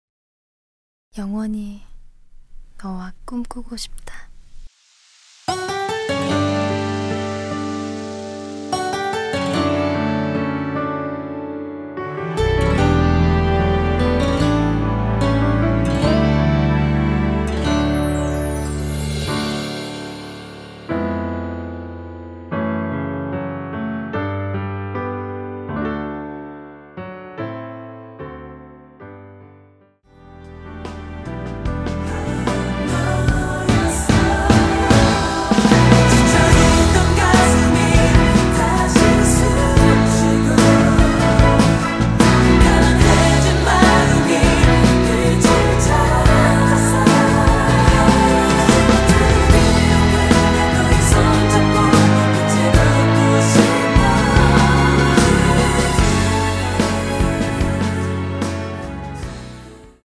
원키에서(-2)내린 멜로디 포함된 MR입니다.
시작 부분 나레이션 들어가 있습니다.(미리듣기 참조)
앞부분30초, 뒷부분30초씩 편집해서 올려 드리고 있습니다.
중간에 음이 끈어지고 다시 나오는 이유는